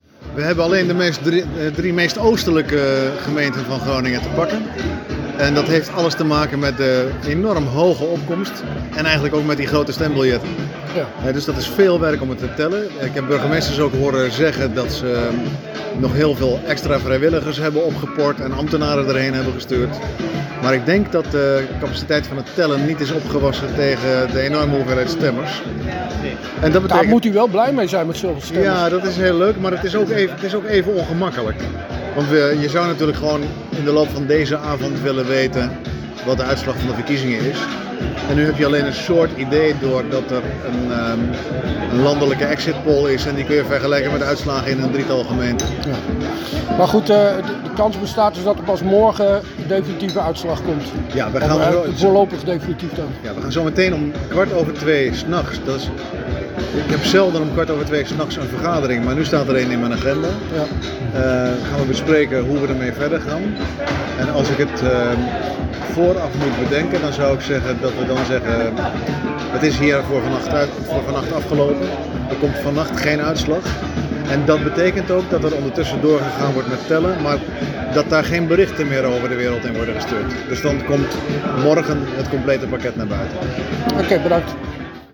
sprak met Paas op het moment dat er nog slechts drie gemeenten waren uitgeteld:
IntvRenePaas.mp3